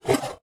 Select Scifi Tab 10.wav